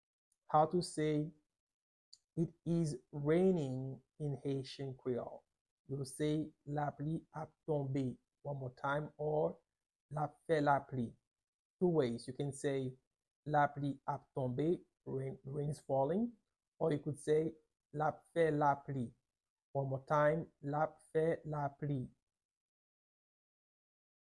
Pronunciation:
11.How-to-say-it-is-raining-in-Haitian-Creole-–-I-ap-fe-lapli-with-Pronunciation.mp3